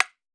PERC - HOUSTON.wav